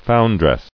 [foun·dress]